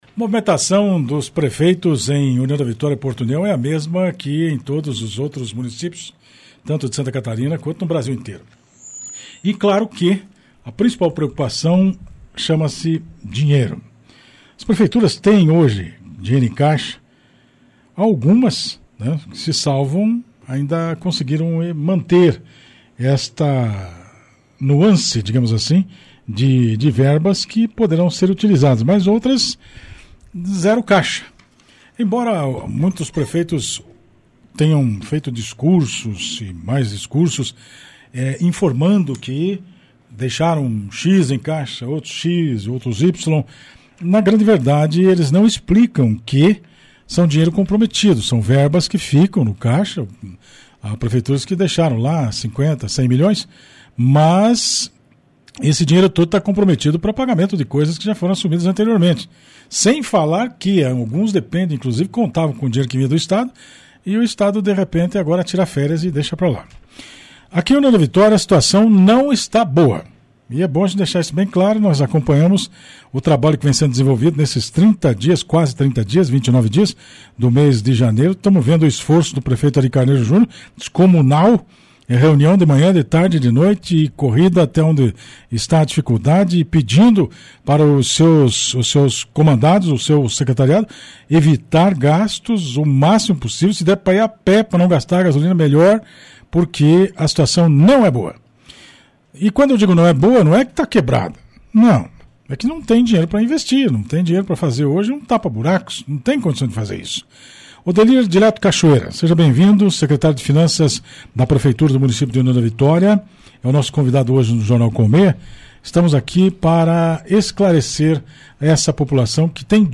Nessa quarta-feira, 29, o Secretário de Finanças de União da Vitória, Odelir Dileto Cachoeira, esteve no Jornal Colmeia que vai ao ar todos os dias ás 12h30, para falar sobre a saúde financeira do município após assumir a pasta nessa nova gestão com o Prefeito Ary Carneiro Júnior.
entrevista-secretario.mp3